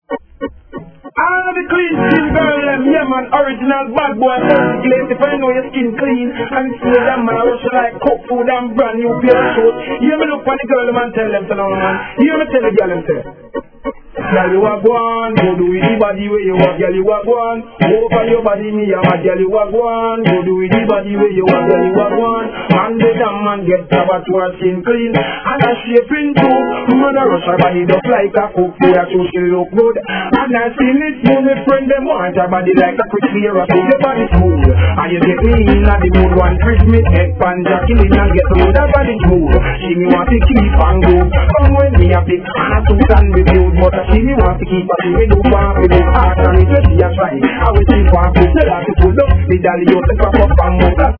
REGGAE
変形RIDDIM!